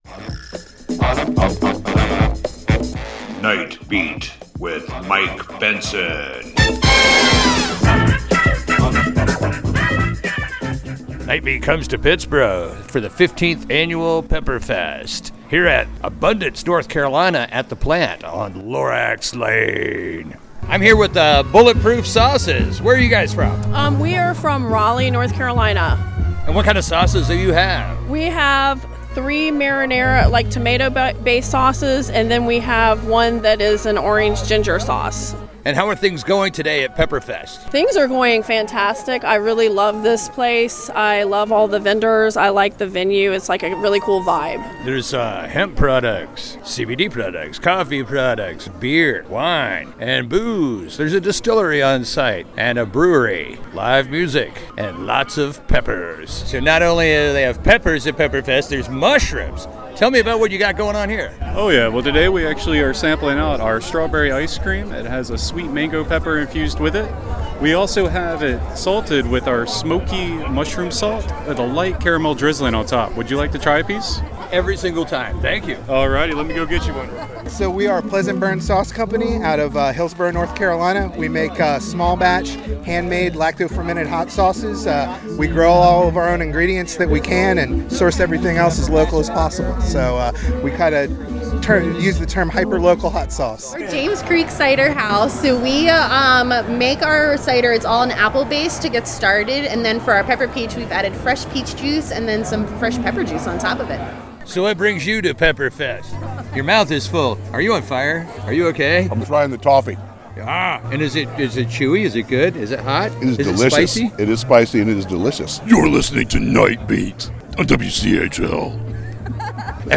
pepperfest.mp3